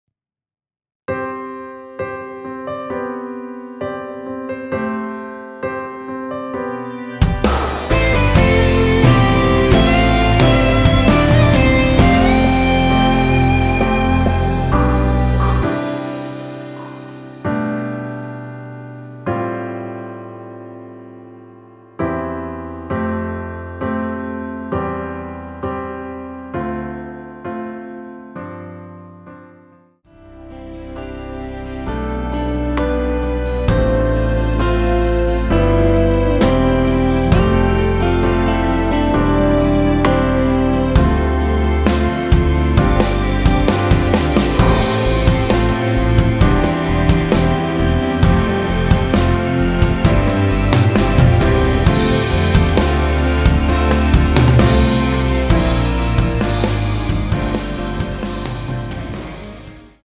원키에서(-1)내린 (1절앞+후렴)으로 진행되는 MR입니다.
앞부분30초, 뒷부분30초씩 편집해서 올려 드리고 있습니다.
중간에 음이 끈어지고 다시 나오는 이유는